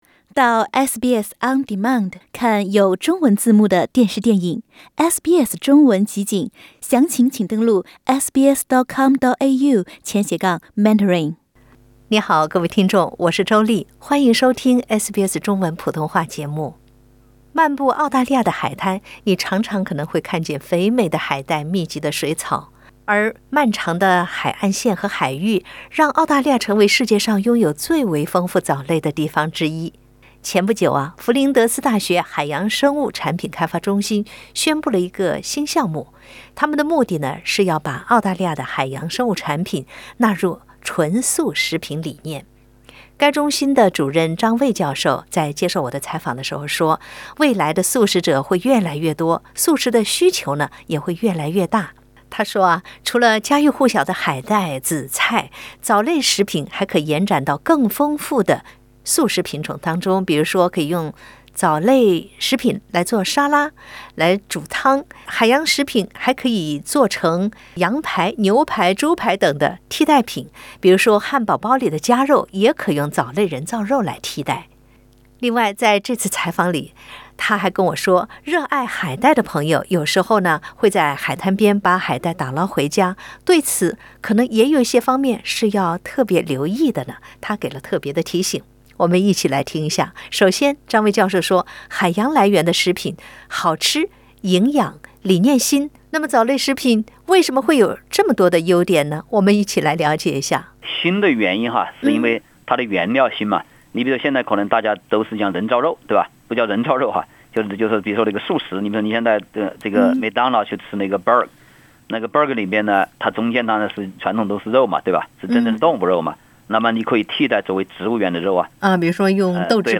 如果汉堡里的夹肉用藻类人造肉来替代，你会喜欢吗？（点击上图收听采访）